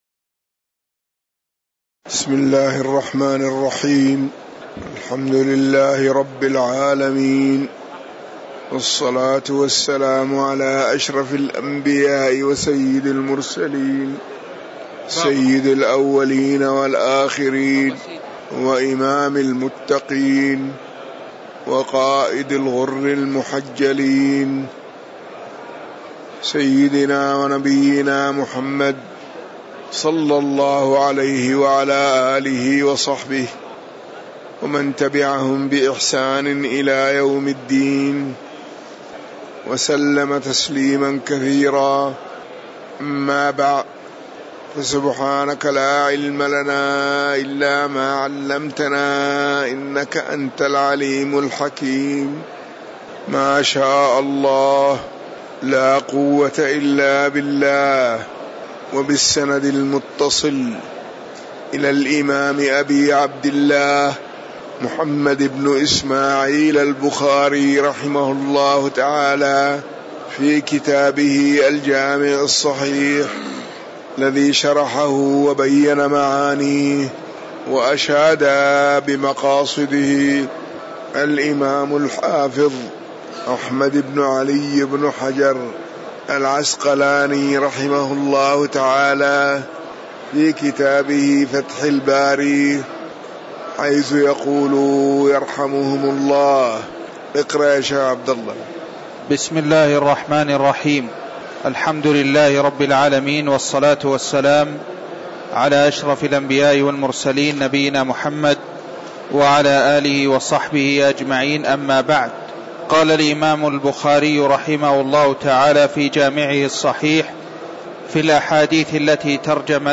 تاريخ النشر ٥ ربيع الثاني ١٤٤١ هـ المكان: المسجد النبوي الشيخ